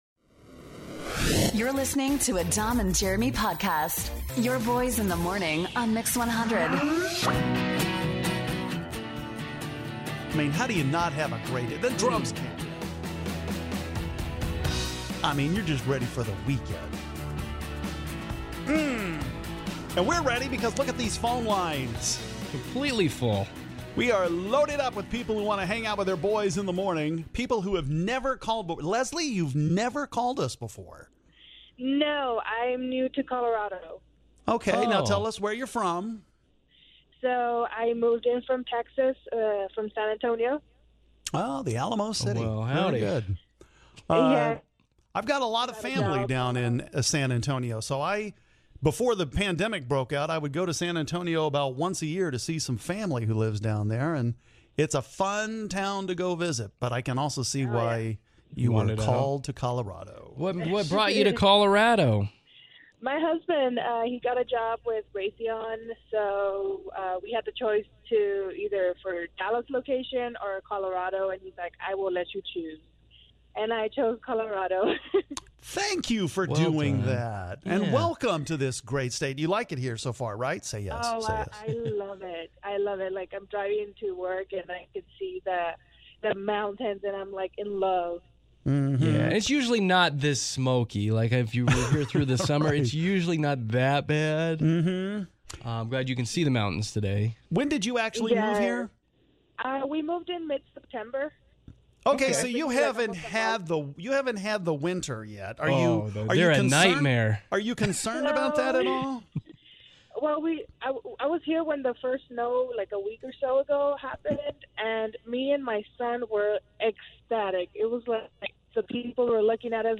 We have some GREAT conversations here with our listeners who have listened for some time, but have never called in!